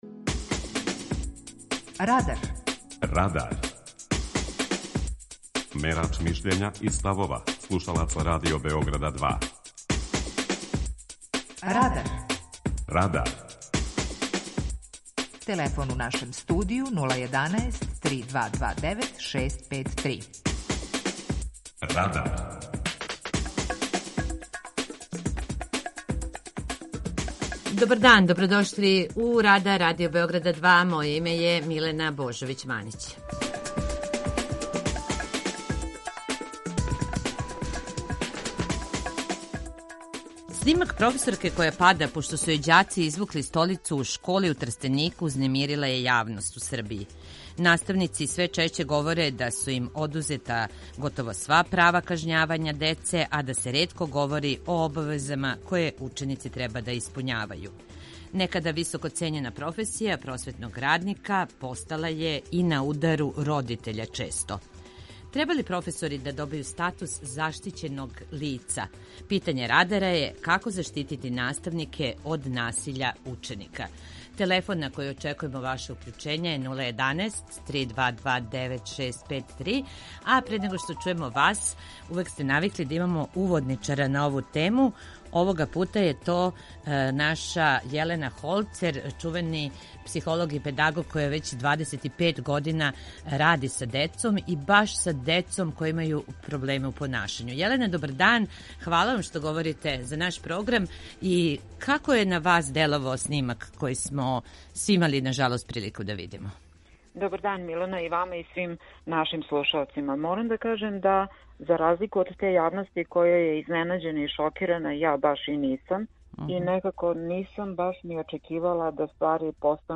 Снимак професорке која пада, пошто су јој ђаци извукли столицу у школи у Трстенику, узнемирила је јавност у Србији. преузми : 18.79 MB Радар Autor: Група аутора У емисији „Радар", гости и слушаоци разговарају о актуелним темама из друштвеног и културног живота.